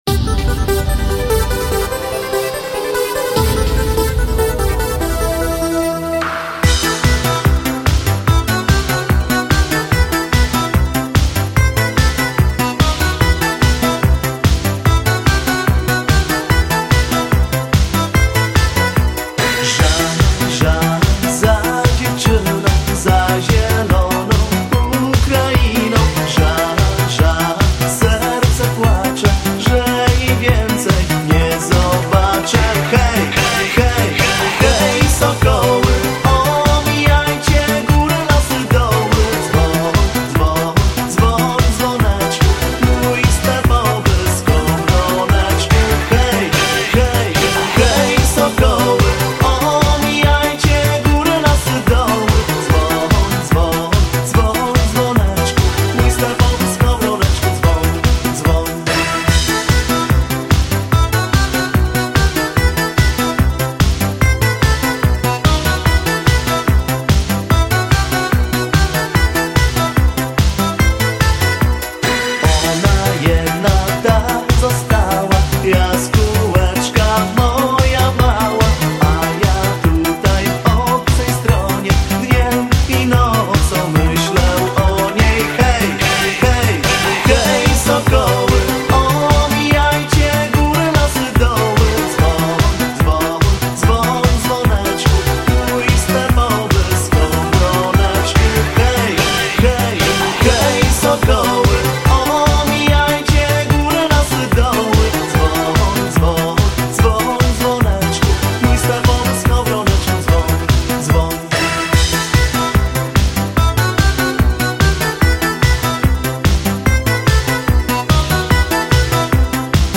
Всі мінусовки жанру Dance
Плюсовий запис